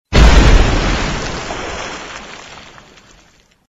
missile_explosion.mp3